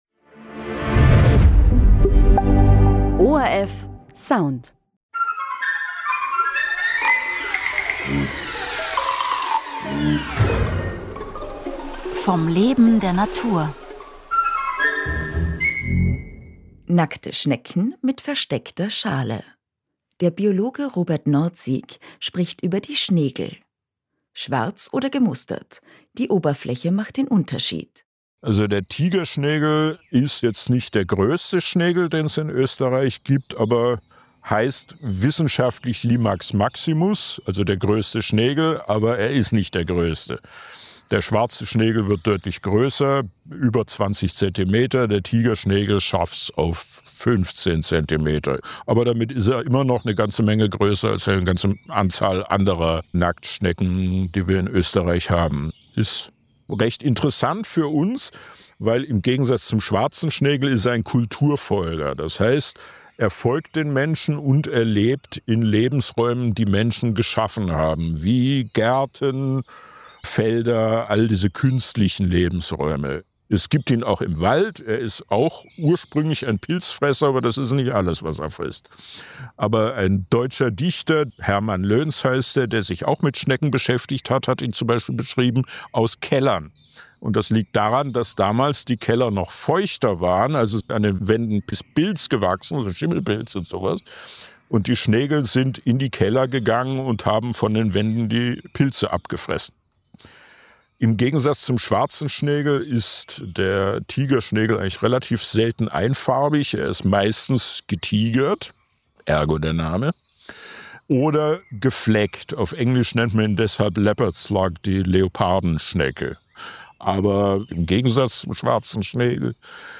Radiointerview im ORF1 Radio